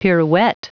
Prononciation du mot pirouette en anglais (fichier audio)